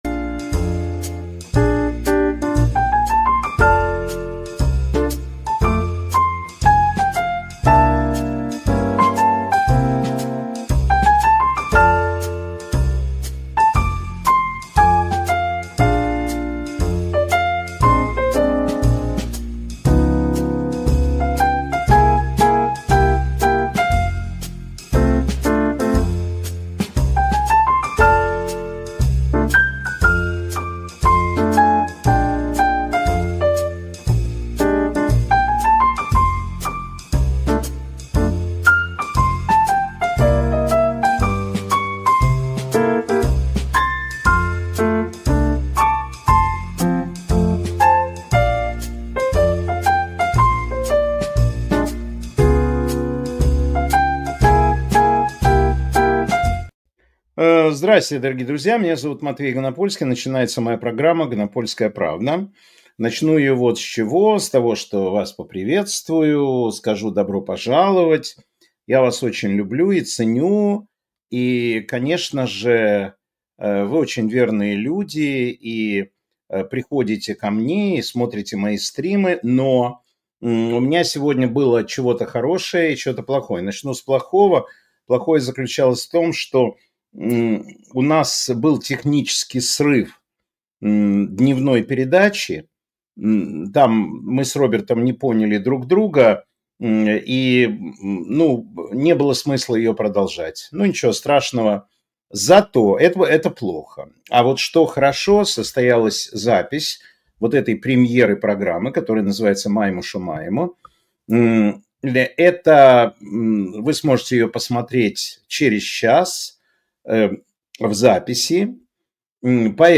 Эфир Матвея Ганапольского